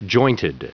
Prononciation du mot : jointed